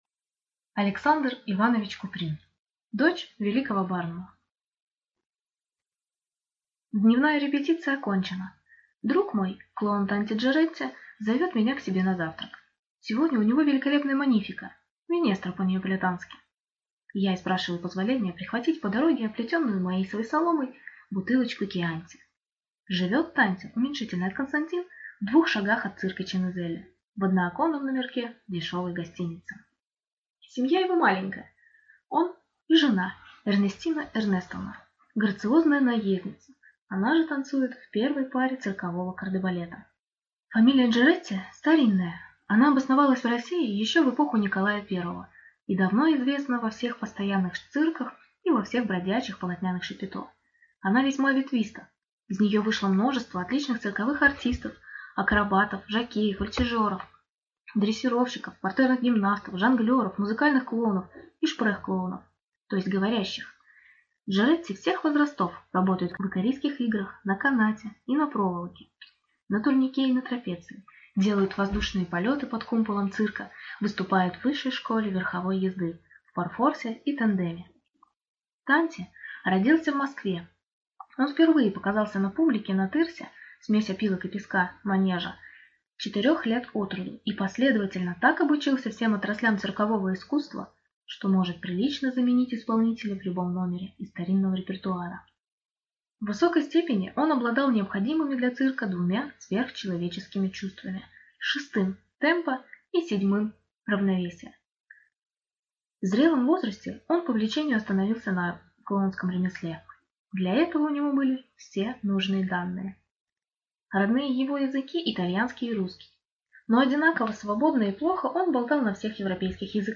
Аудиокнига Дочь великого Барнума | Библиотека аудиокниг